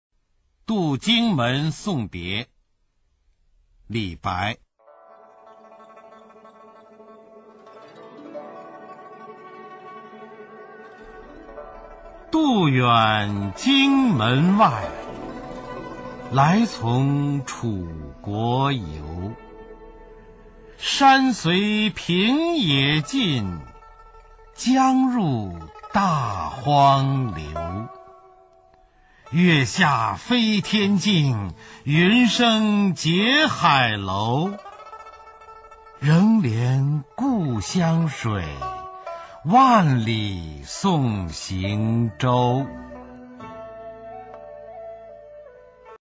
[隋唐诗词诵读]李白-渡荆门送别 唐诗吟诵